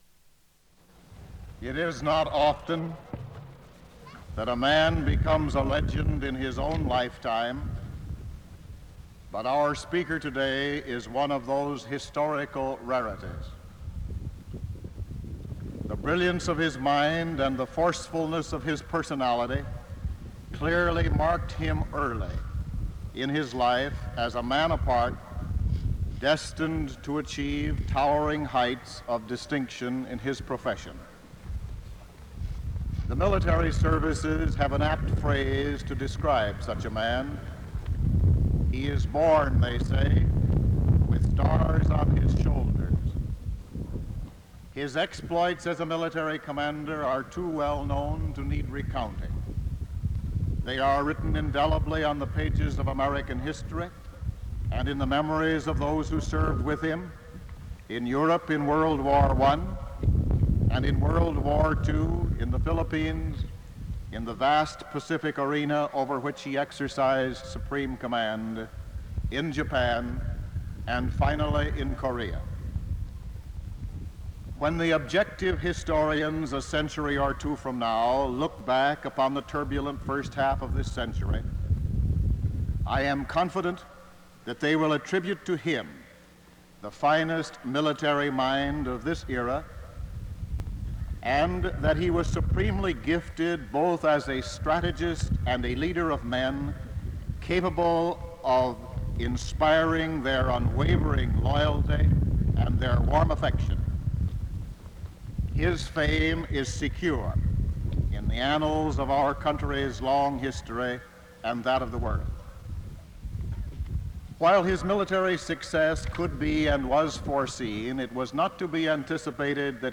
Commencement Address, Spring 1961
Commencement Address, Spring 1961 Back Creator: WKAR Subjects: Faculty, Students, Presidents, World War II, Commencements Description: Former General of the Army Douglas MacArthur speaks on a windy day at the June 11, 1961 MSU commencement. Date: June 11, 1961 Format: Audio/mp3 Original Format: Open reel audio tape Resource Identifier: A007593 Collection Number: UA 3.3.5.3 Language: English Rights Management: Educational use only, no other permissions given.